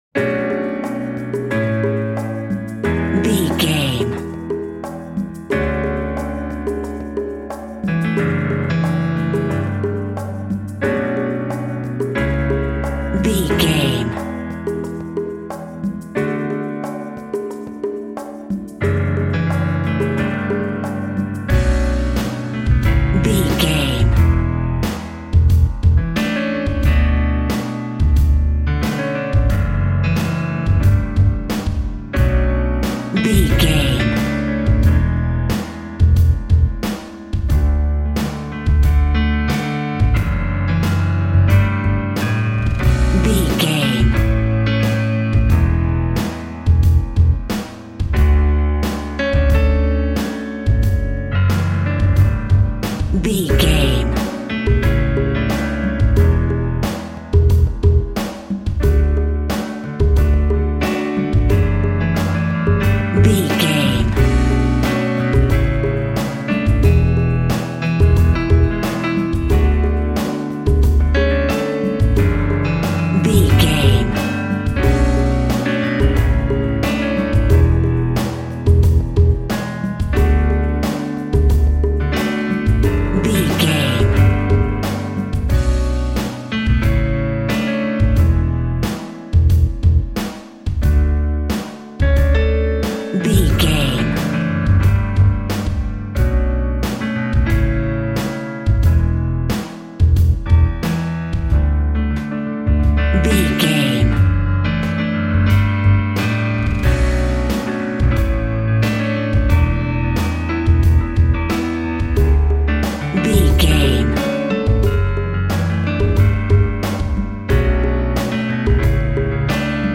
Hip Hop Acoustic.
Aeolian/Minor
Slow